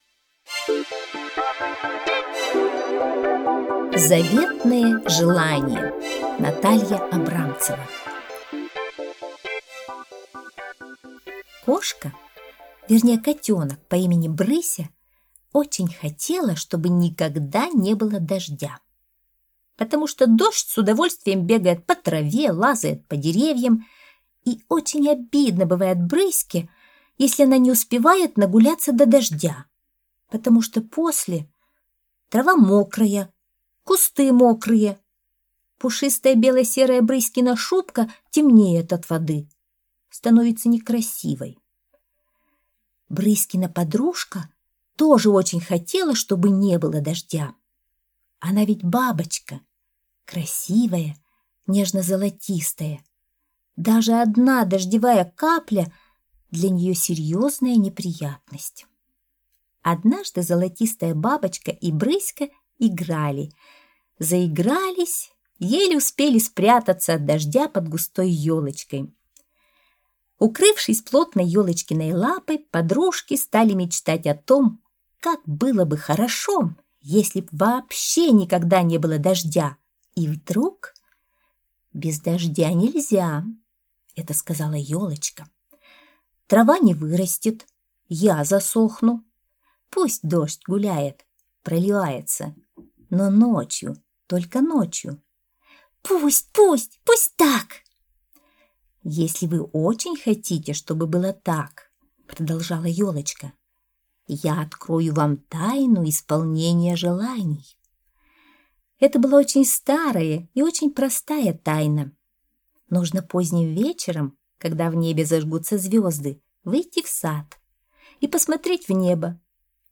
Заветное желание - аудиосказка Натальи Абрамцевой - слушать онлайн